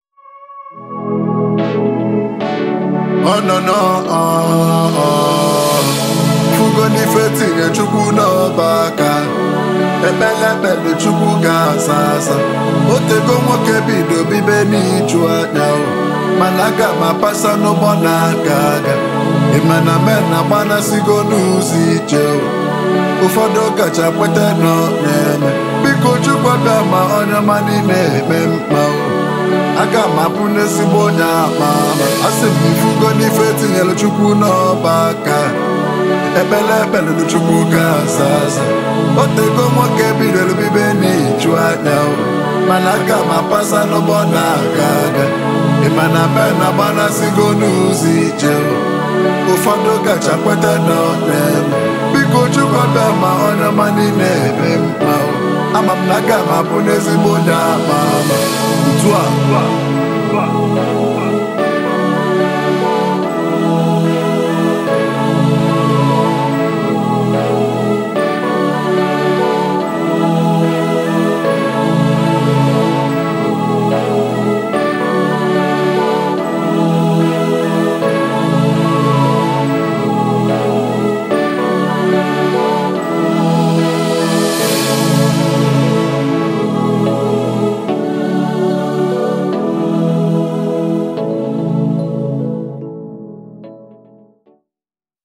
Afro Fuji Pop